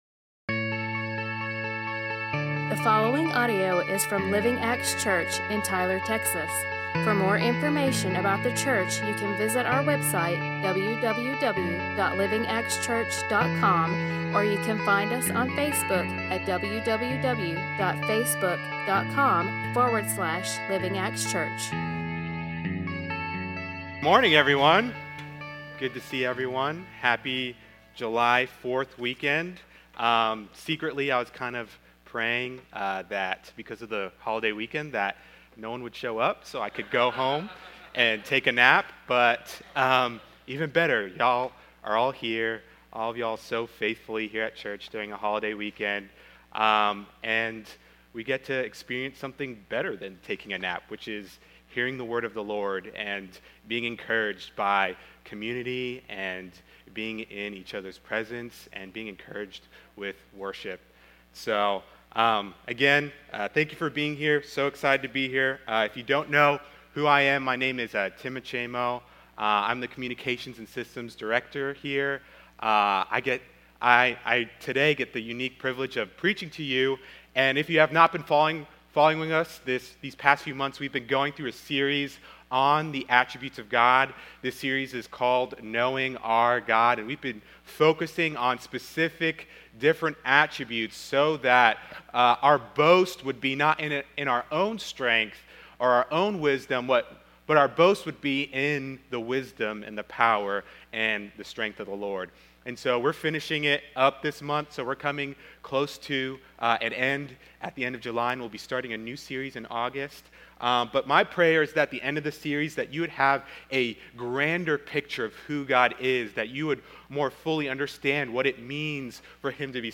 A message from the series "Knowing Our God."